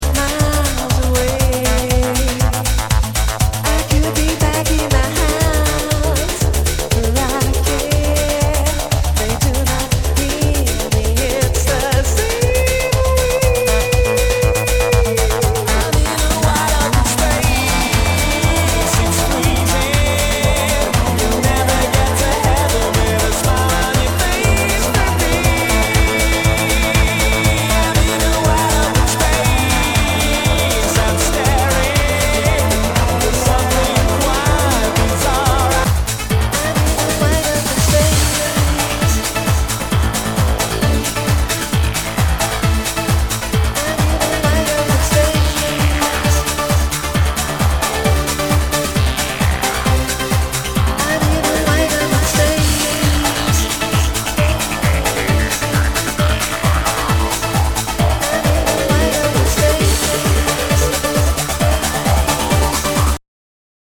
HOUSE/TECHNO/ELECTRO